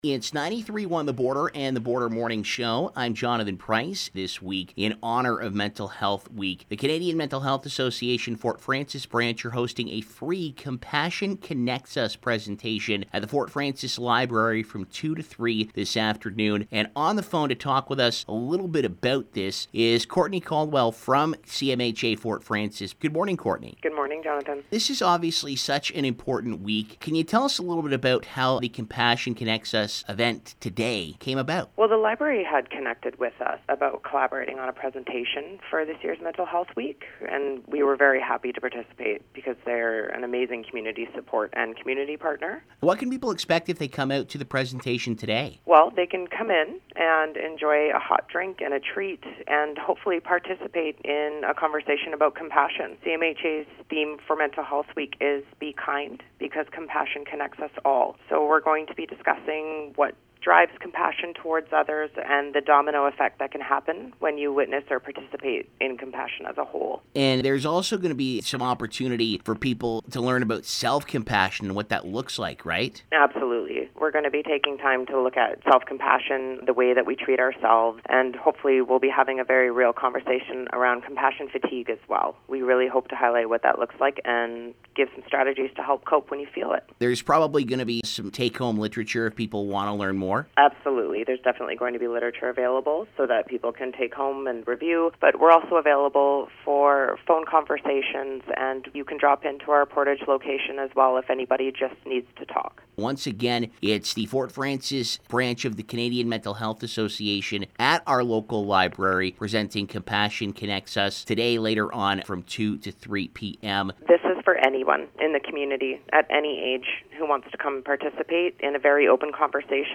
Hear a representative from CMHA Fort Frances speak about what you can expect if you attend and take part…